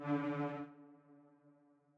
Buildup_3.wav